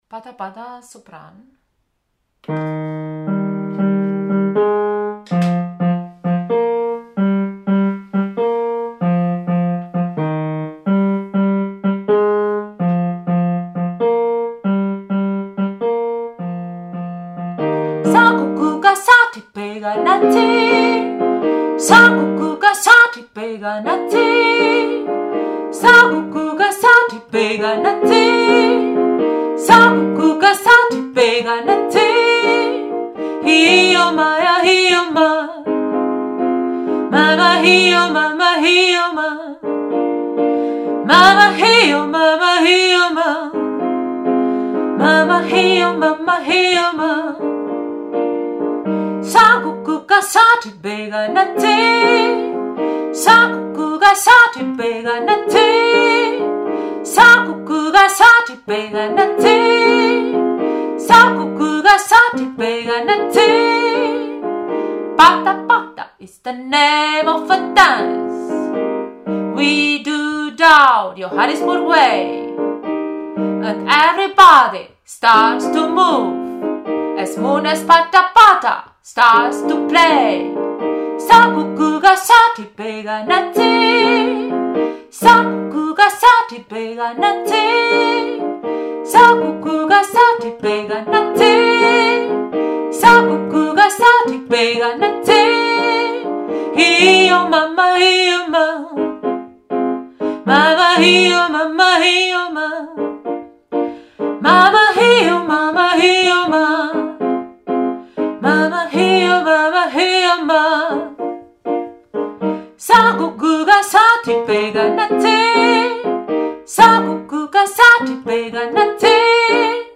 Pata Pata – Sopran1
Pata-Pata-Sopran1.mp3